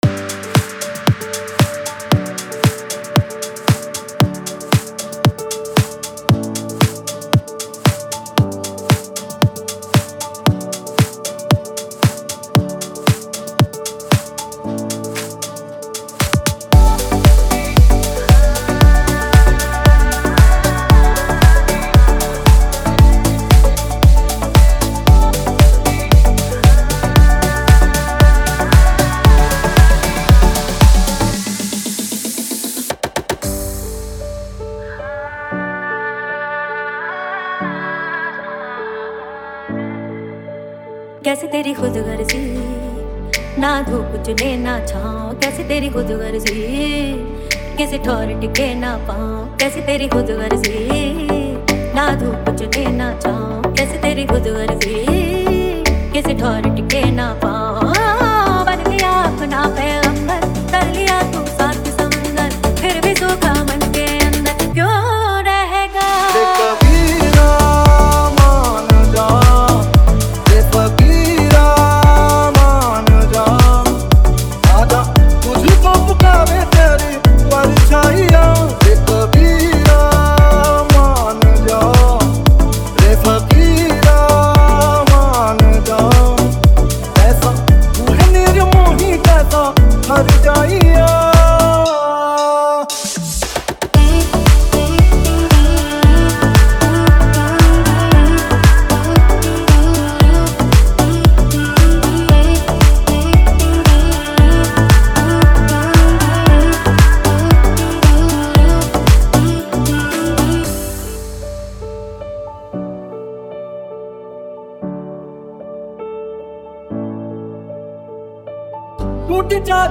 Bollywood Deep House
Bollywood DJ Remix Songs